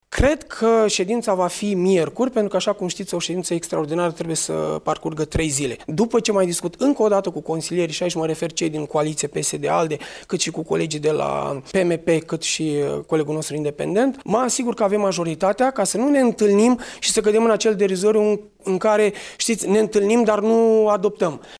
În schimb, viceprimarul Gabriel Harabagiu a declarat că nu este vorba de blocarea proiectului şi că termenul de trimitere a planului urbanisic zonal, 31 martie, va fi respectat.